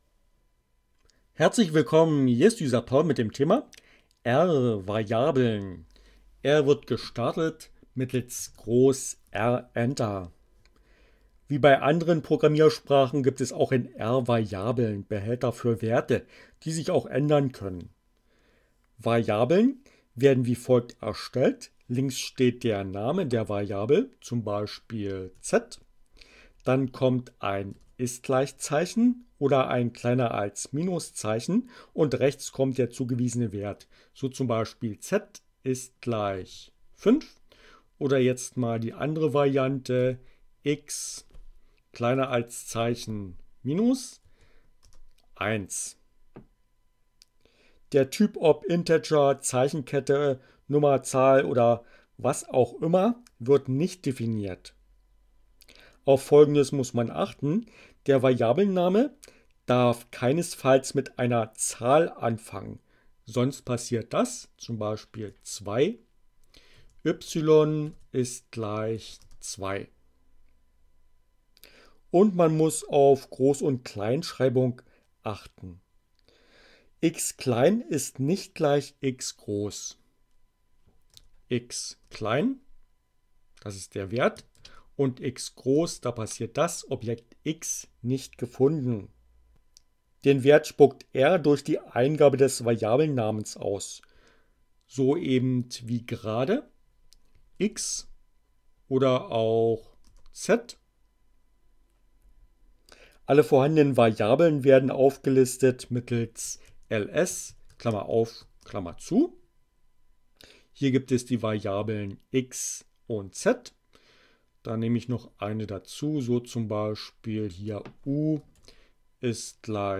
Tags: CC by, Linux, Neueinsteiger, ohne Musik, screencast, R